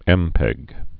(ĕmpĕg)